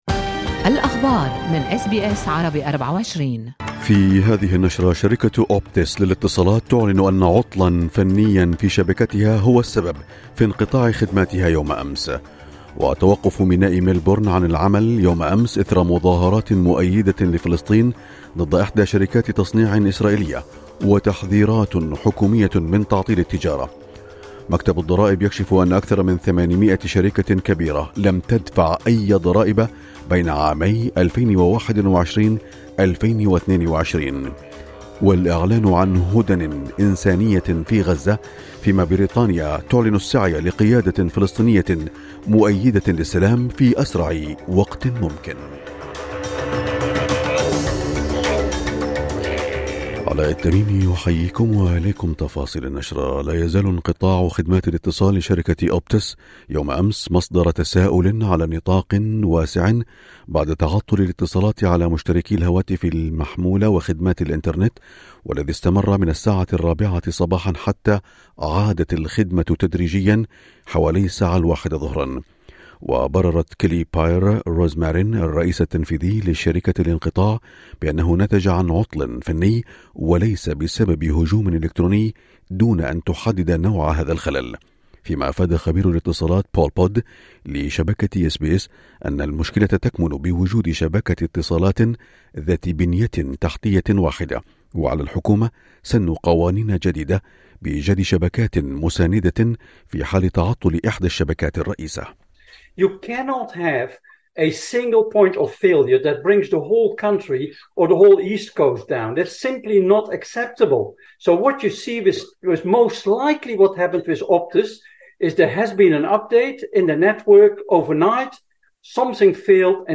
نشرة أخبار الصباح 9/11/2023